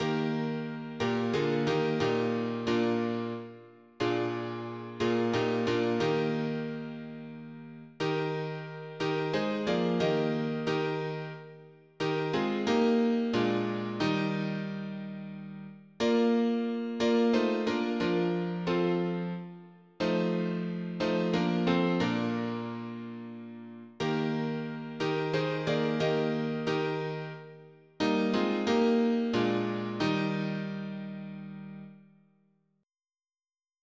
MIDI Music File
General MIDI